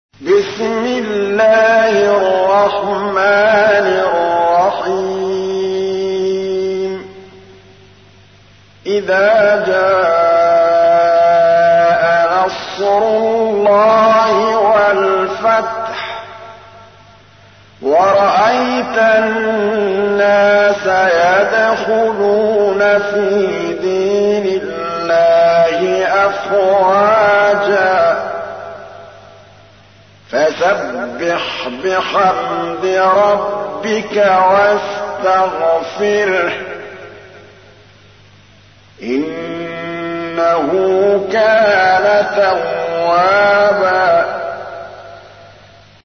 تحميل : 110. سورة النصر / القارئ محمود الطبلاوي / القرآن الكريم / موقع يا حسين